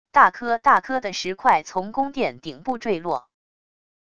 大颗大颗的石块从宫殿顶部坠落wav音频